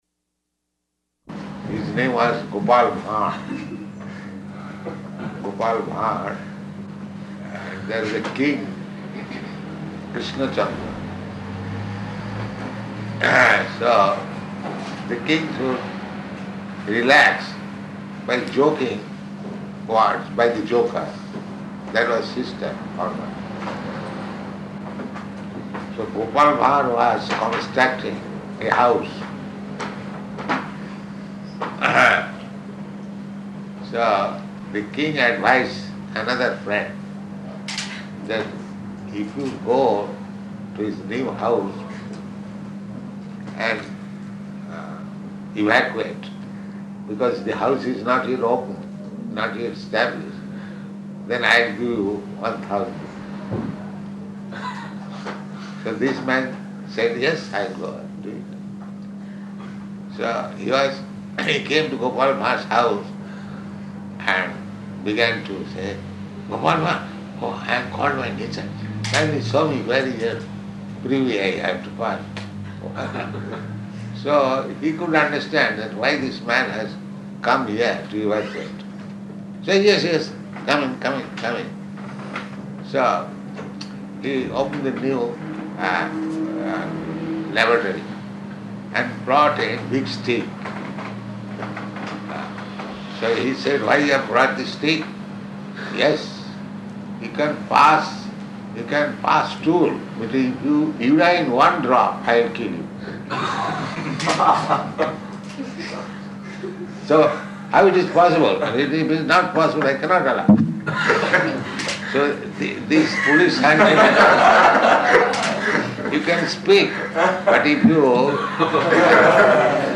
Room Conversation
Room Conversation --:-- --:-- Type: Conversation Dated: August 11th 1973 Location: Paris Audio file: 730811R1.PAR.mp3 Prabhupāda: His name was Gopal Ban.